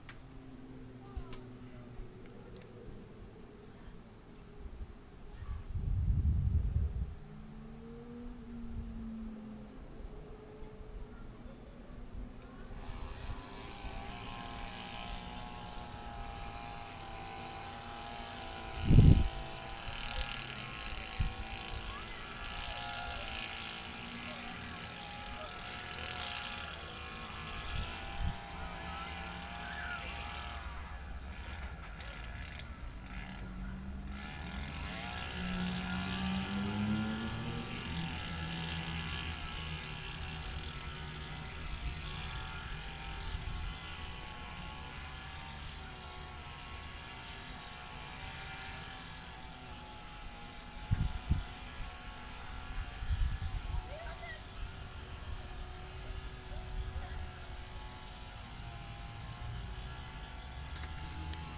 Summer on the playing field in Alston